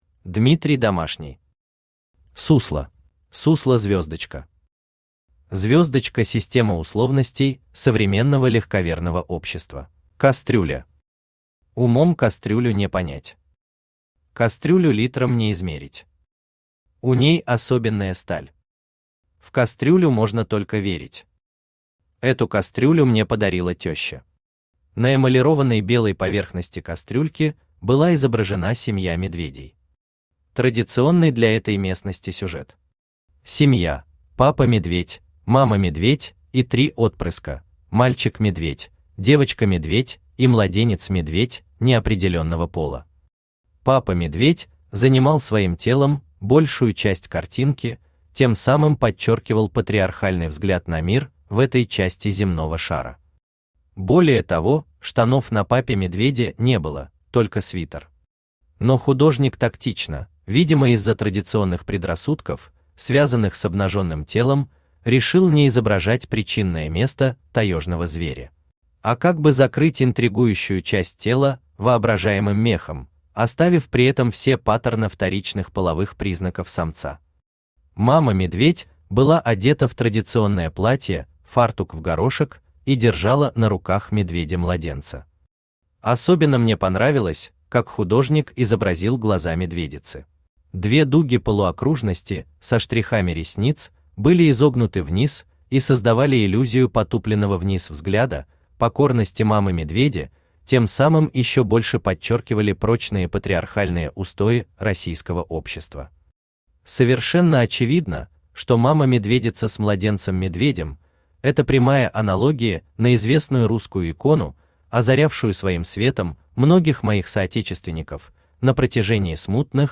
Аудиокнига СУСЛО | Библиотека аудиокниг
Aудиокнига СУСЛО Автор Дмитрий Домашний Читает аудиокнигу Авточтец ЛитРес.